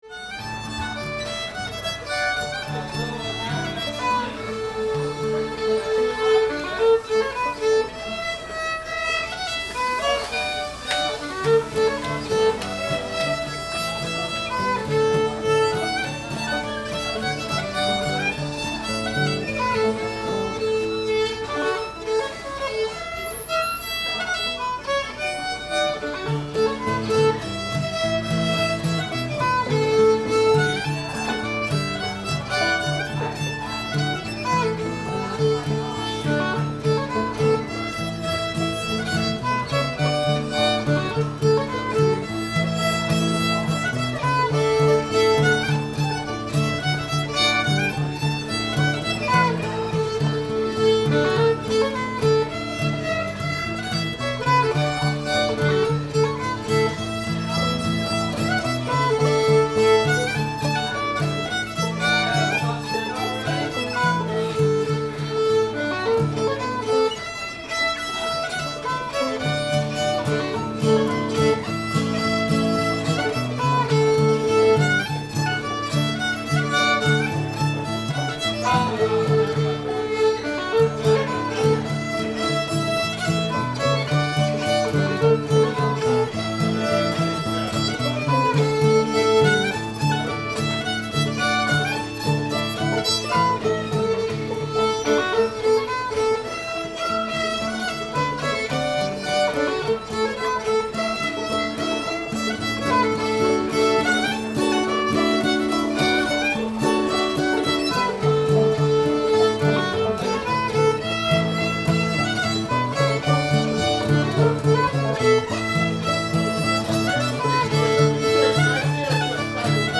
going across the sea [D]